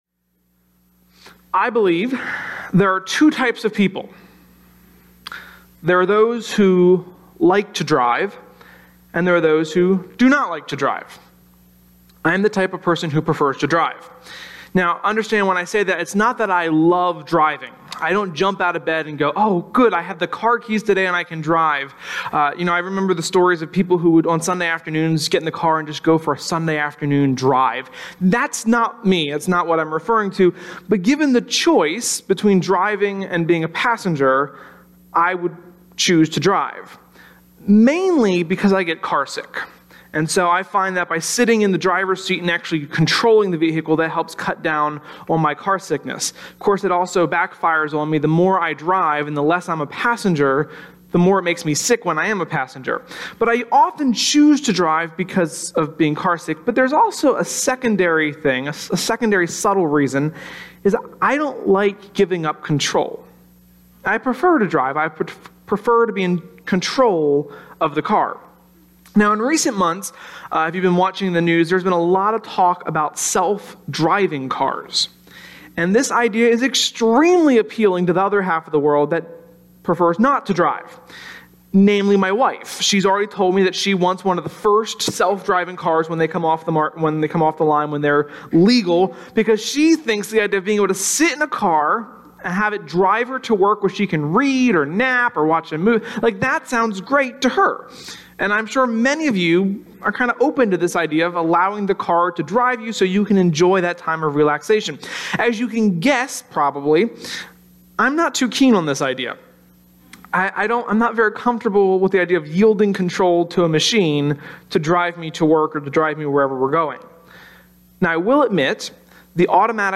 sermon-2.25.18.mp3